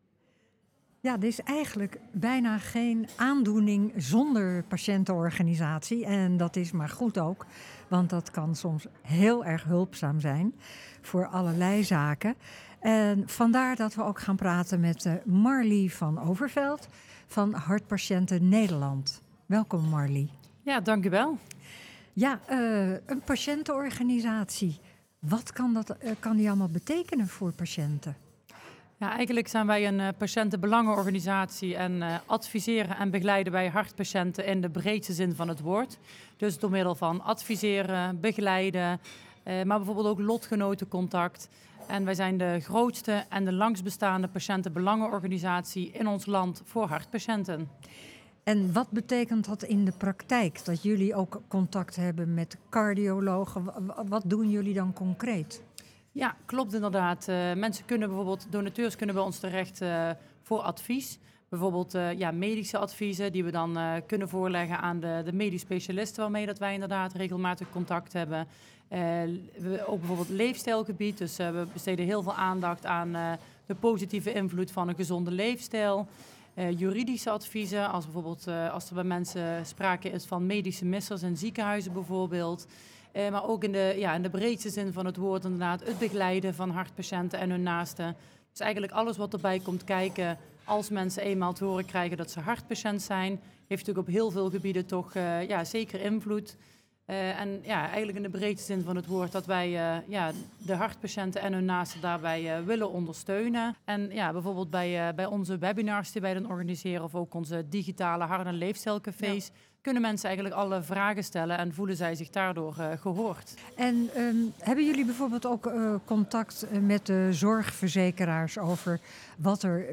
In dit interview vertelt zij over het belang van belangenbehartiging  en geeft voorbeelden van bereikte resultaten.